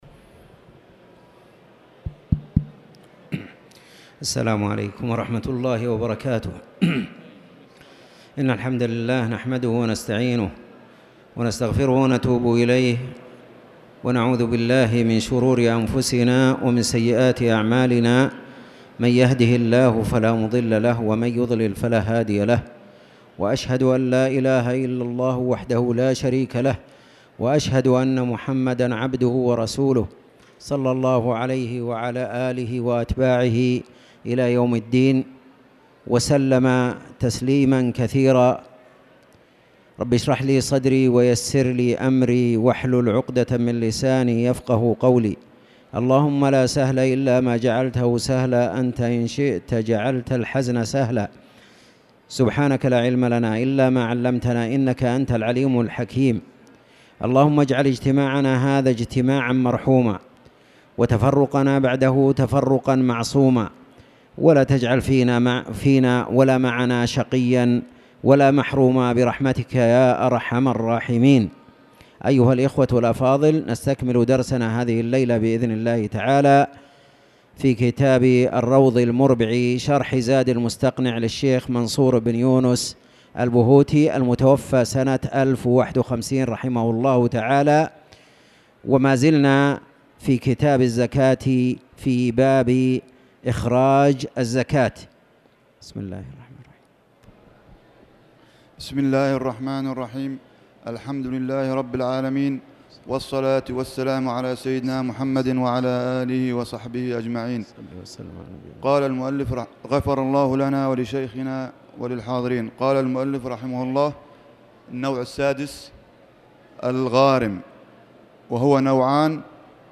تاريخ النشر ٢٢ صفر ١٤٣٨ هـ المكان: المسجد الحرام الشيخ